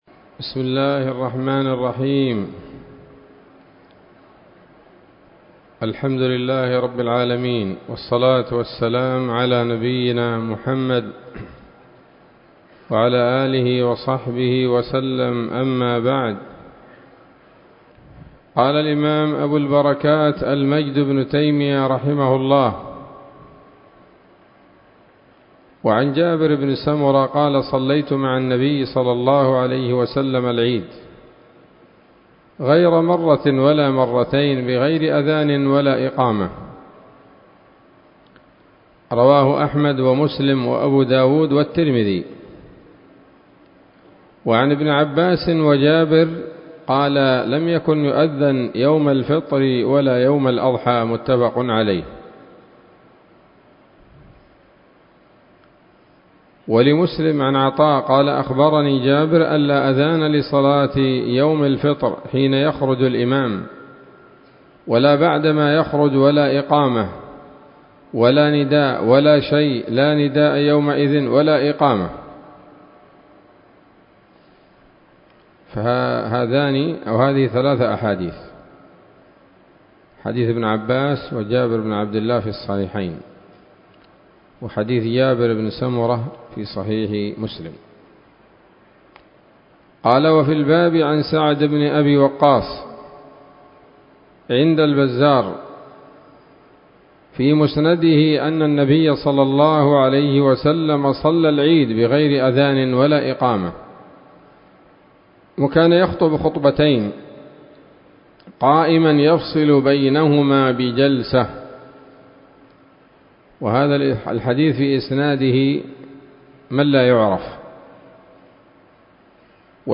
الدرس التاسع من ‌‌‌‌كتاب العيدين من نيل الأوطار